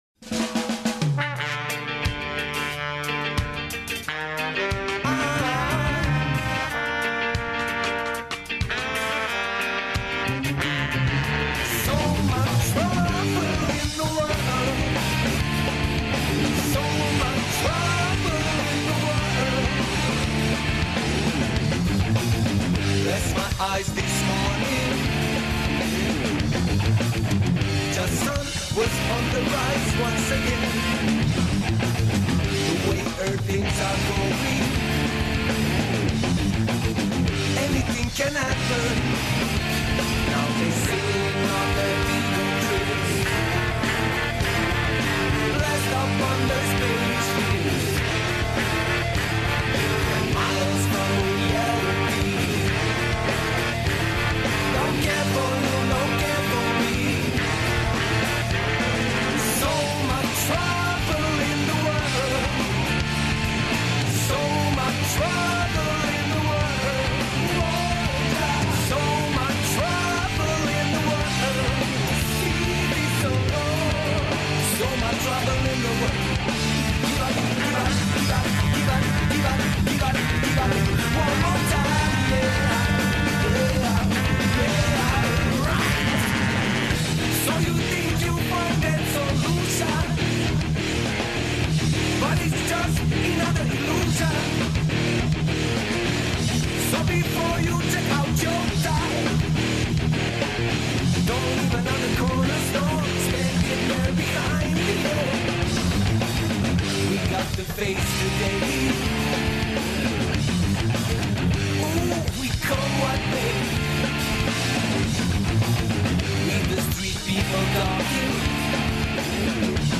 Пратимо и други дан Lovefest-a, који се одржава у Врњачкој Бањи.